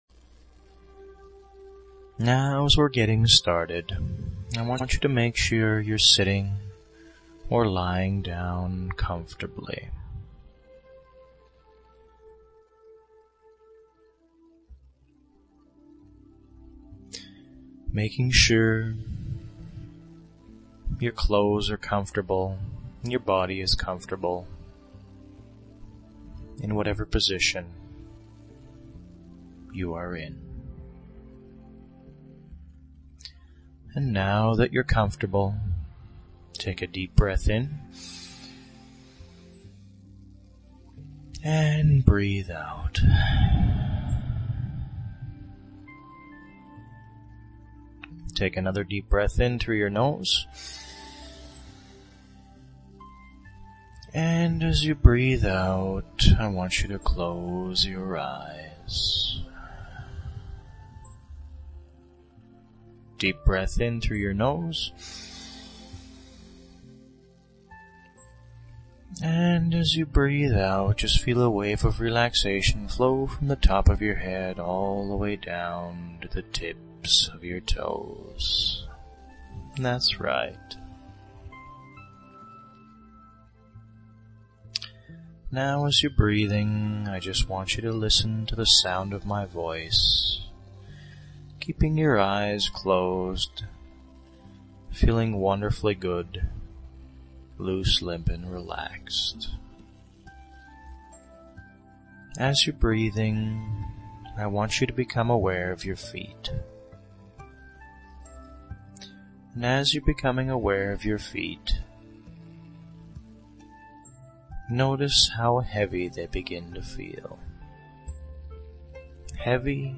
Hypnosis Relaxation MP3